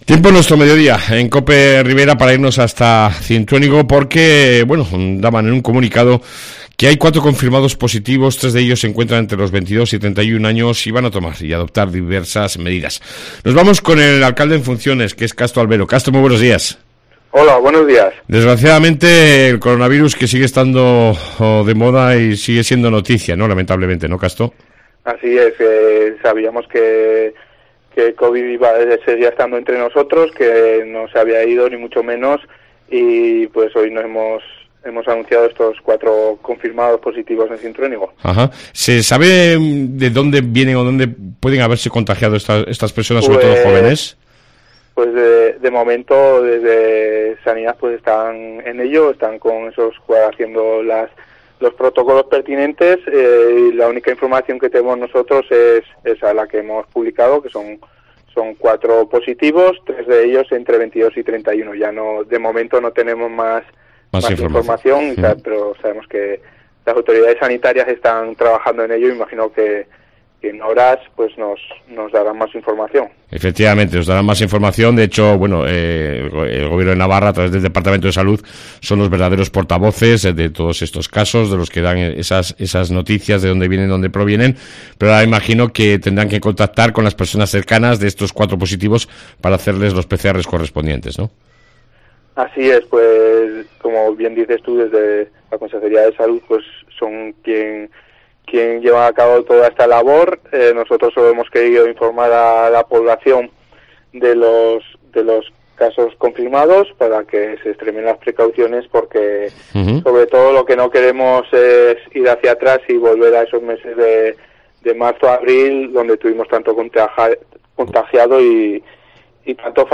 AUDIO: Hablamos con el Alcalde en funciones Casto Alvero